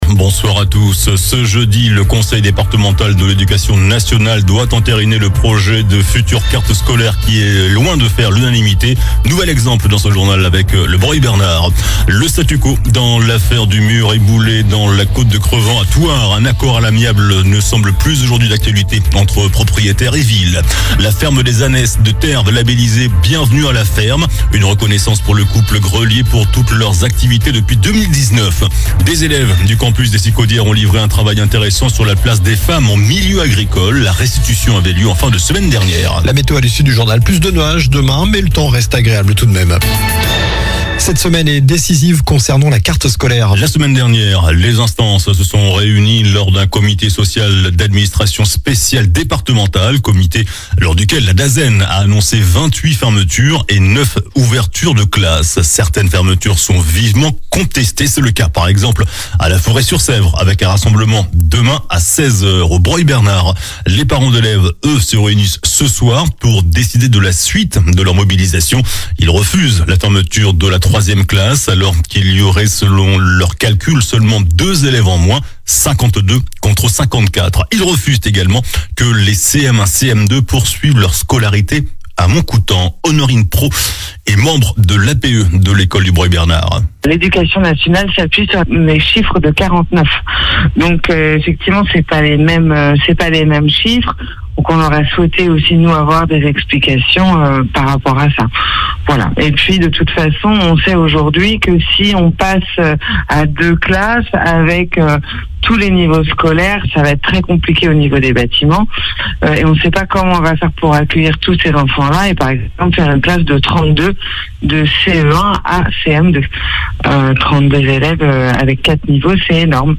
Journal du lundi 17 février (soir)